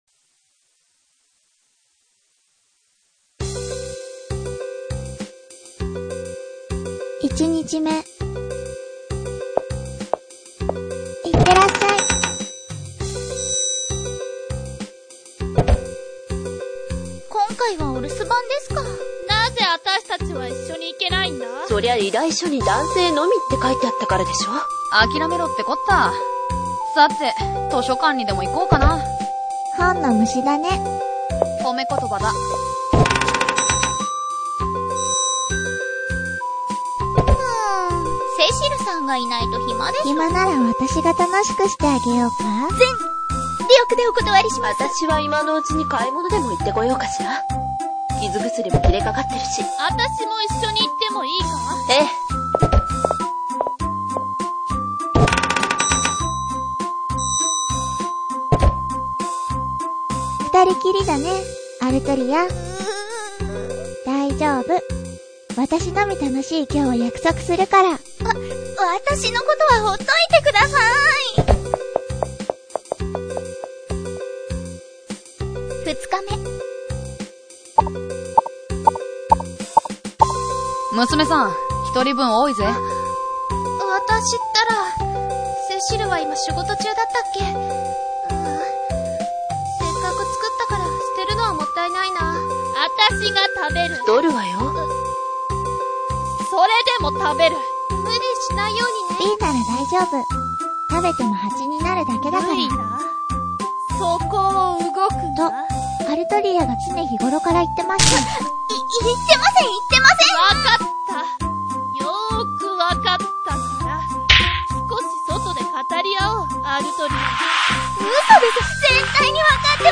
BGM/SE 音楽 煉獄庭園/ON-Jin　〜音人〜